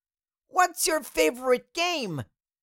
Cartoon Little Child, Voice, What Is Your Favorite Game Sound Effect Download | Gfx Sounds
Cartoon-little-child-voice-what-is-your-favorite-game.mp3